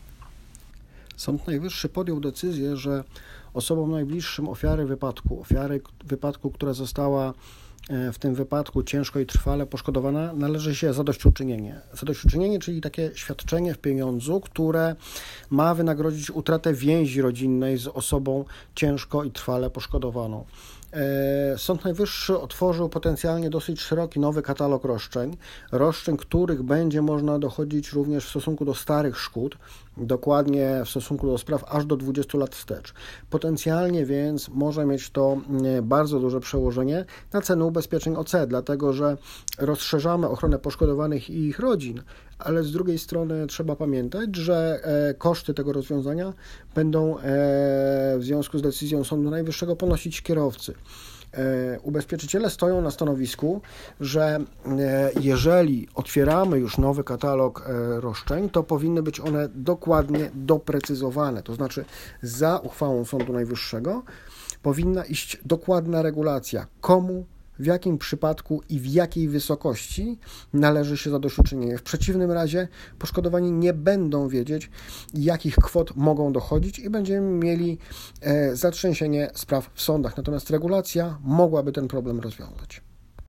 Na gorąco komentarz